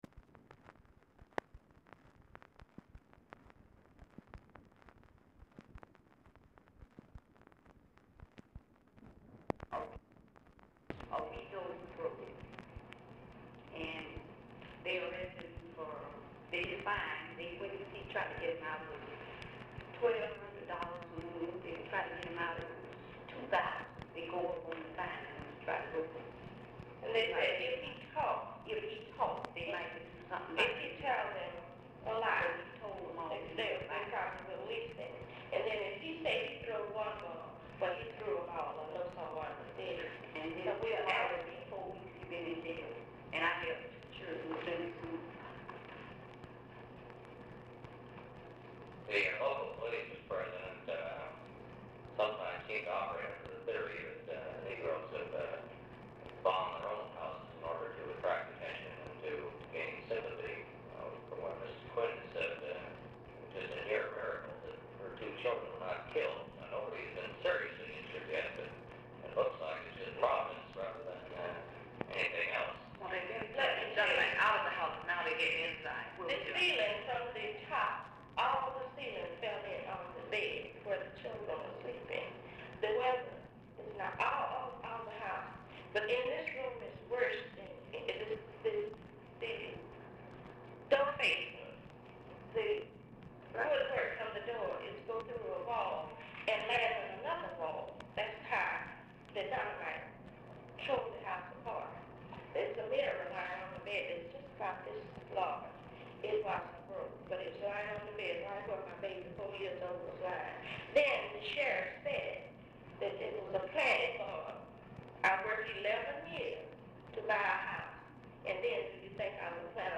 Telephone conversation # 5667, sound recording, LBJ and OFFICE CONVERSATION, 9/24/1964, 1:10PM | Discover LBJ
Format Dictation belt
Location Of Speaker 1 Oval Office or unknown location
Specific Item Type Telephone conversation